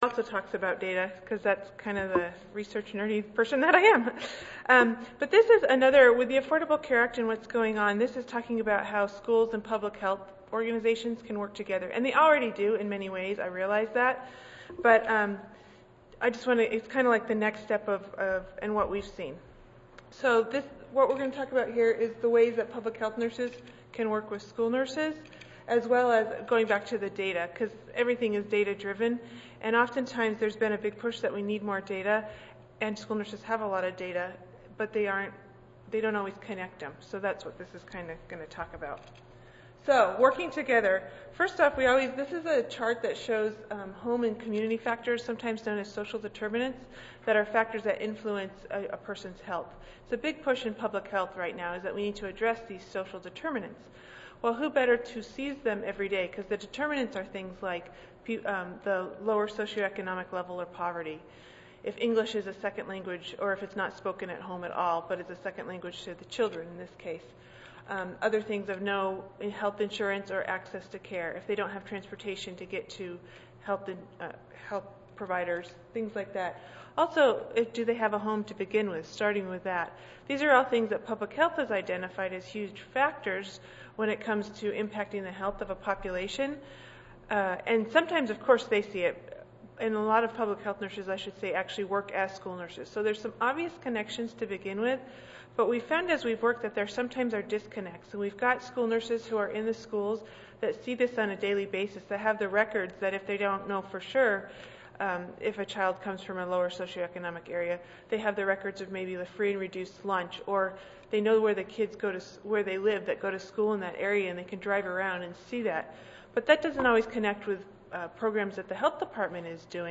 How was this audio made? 141st APHA Annual Meeting and Exposition (November 2 - November 6, 2013): School and public health nurses: Partners in population-based school health data and programs